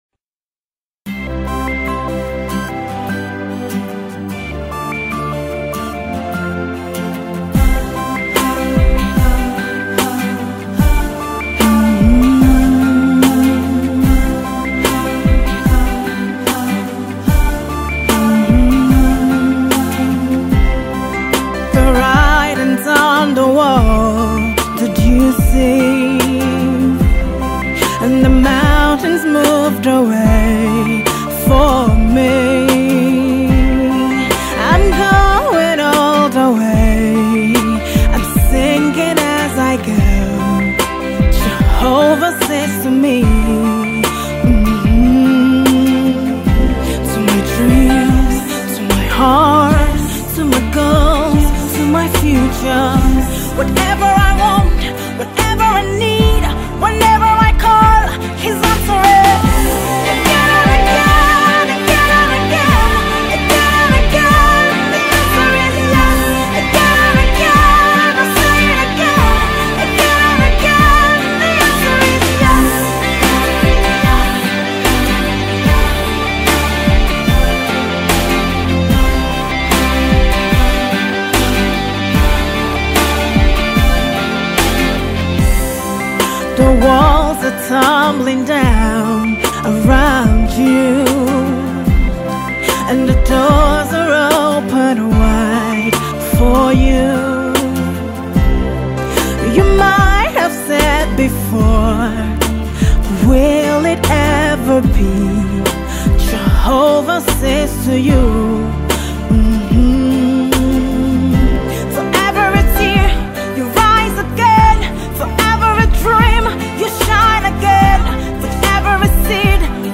Christian & Gospel SongsNigerian Gospel Songs
Genre:Gospel